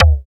SI2 FM KICK.wav